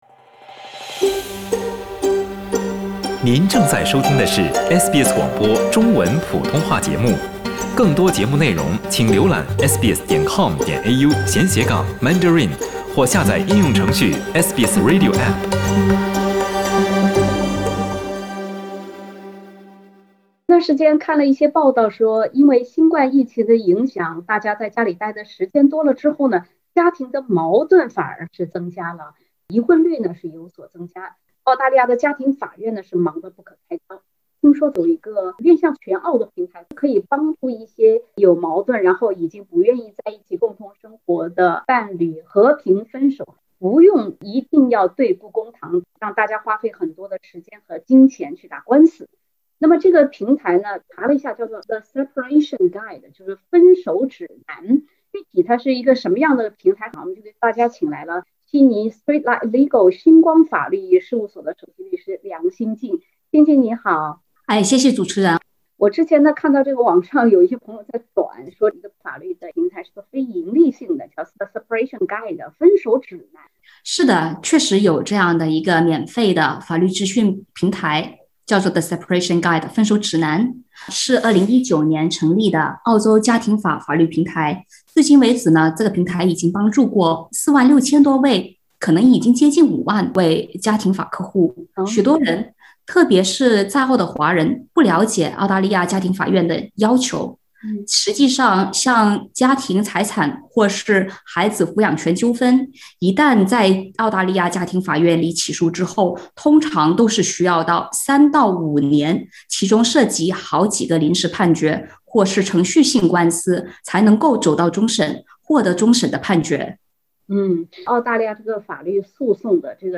疫情“家里蹲”久了出现婚姻问题，可以试试看能得到什么帮助。（点击图片，收听完整采访）